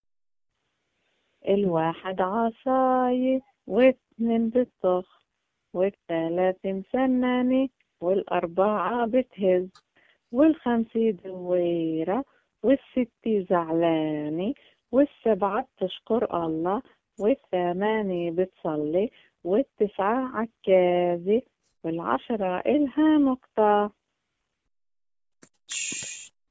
aa = a kind of "ah" sound pronounce very deep in the throat
kh = like "j" in Spain Spanish or "ch" in German "Bach"
q = "dark k", pronounced raising the soft palate